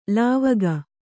♪ lāvaga